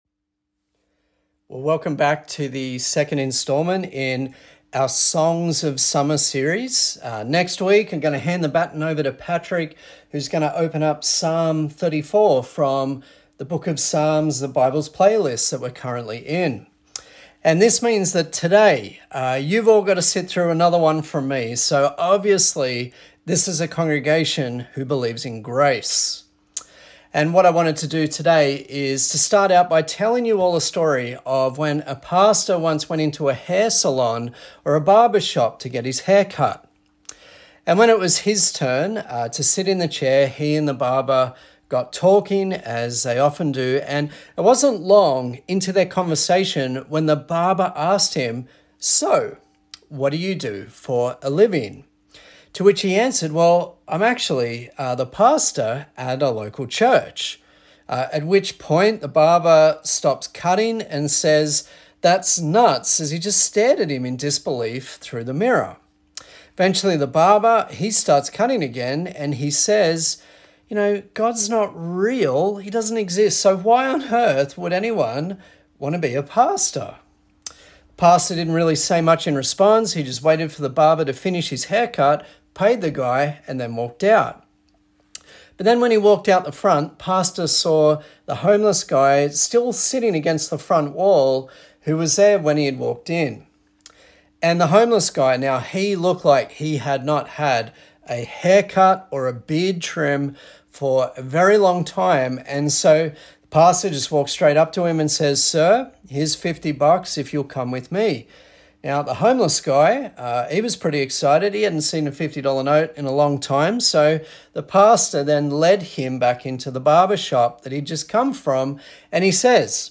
A sermon in the serious 'Songs for Summer' featuring the book of Psalms.
Psalm 19 Service Type: Sunday Service A sermon in the serious 'Songs for Summer' featuring the book of Psalms.